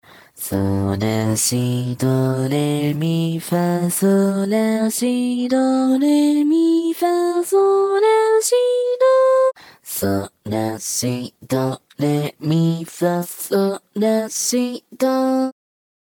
藤咲透明_JPVCV_穏やか（Re gentle）推薦
収録音階：E3 G3 B3 D4（裏声+息）             DL
G4（裏声）+G3（エッジっぽい連続音）